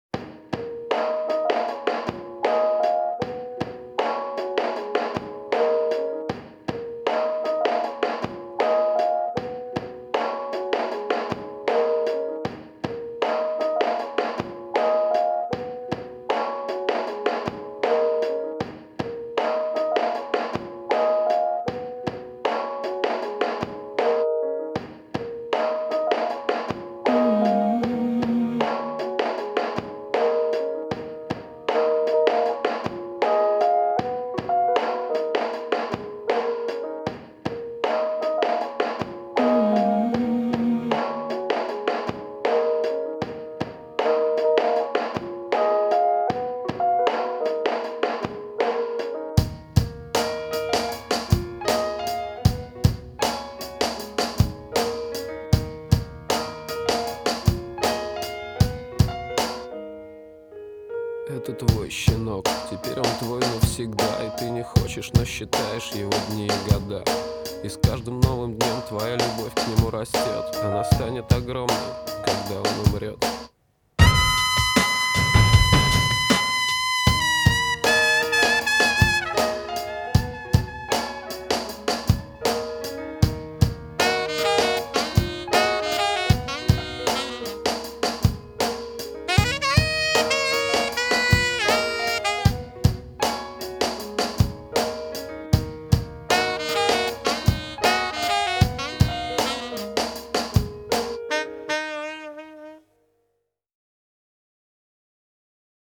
Жанр: local-indie